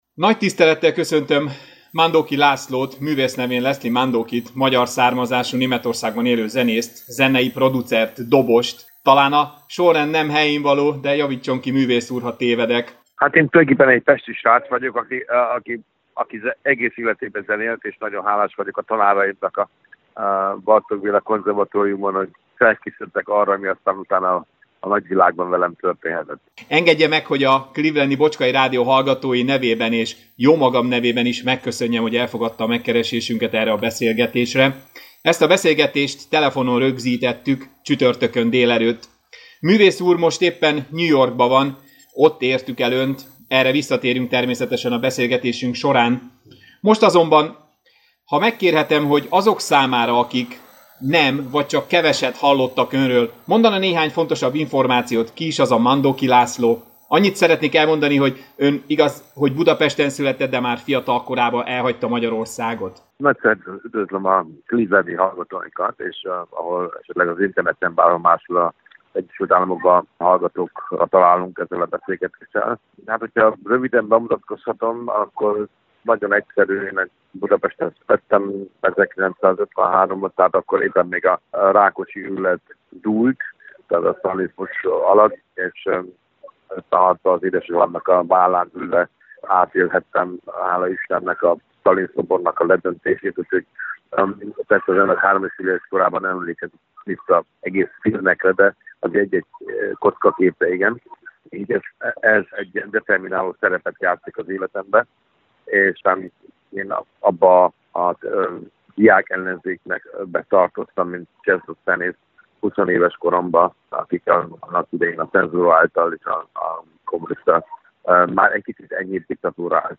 December 7-én telefonon értük el New Yorkban a Mandoki művész urat akivel a januári koncertjükről, annak előkészületeiről kérdeztük.
Mandoki-Laszlo-interju-Javitott.mp3